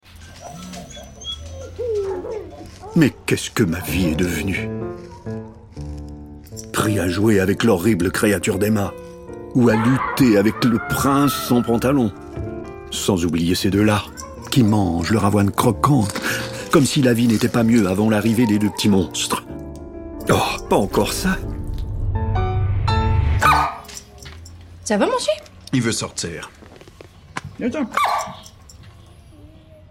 Yves Jacques_voix chien_Oatmeal Crisp.mp3